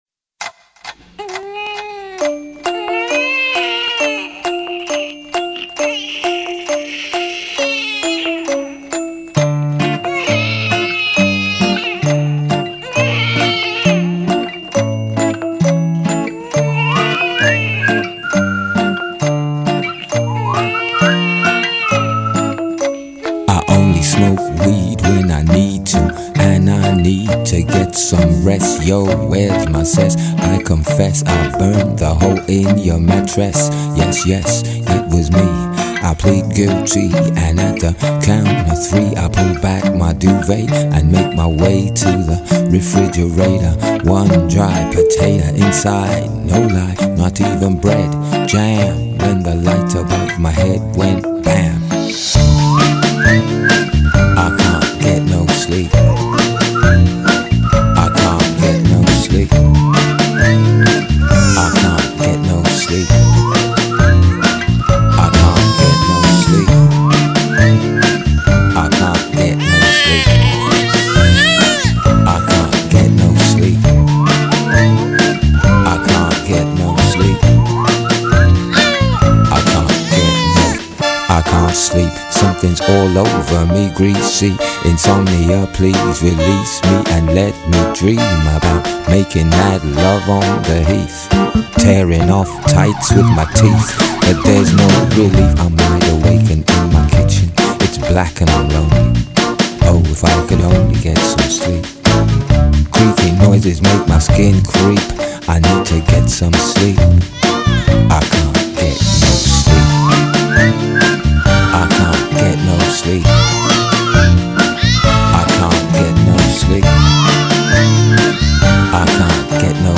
Bootlegs (page 8):